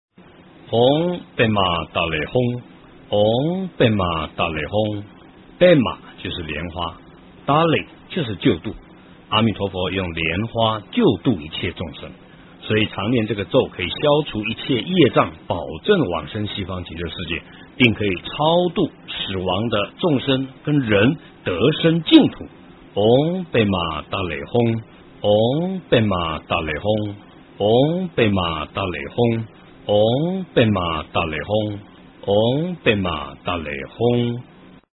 阿弥陀佛往生心咒 - 诵经 - 云佛论坛
阿弥陀佛往生心咒 诵经 阿弥陀佛往生心咒--海涛法师 点我： 标签: 佛音 诵经 佛教音乐 返回列表 上一篇： 阿弥陀佛 下一篇： 阿弥陀经 相关文章 灵量之路--Monjes Budistas 灵量之路--Monjes Budistas...